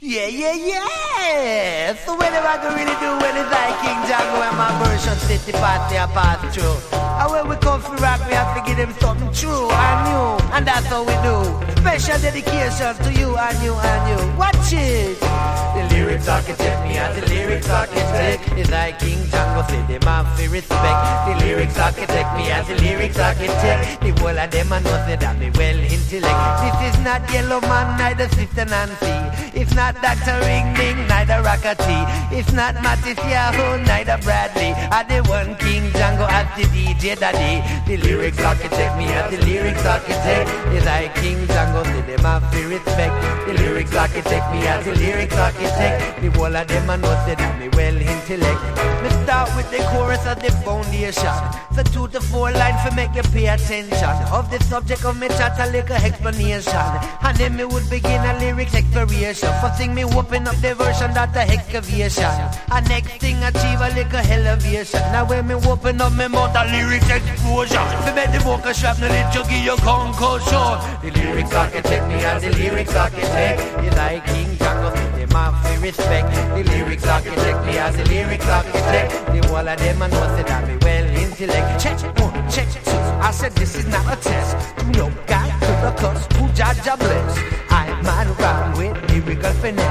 センス抜群の男泣きレゲエチューンが収録されているマストアイテム！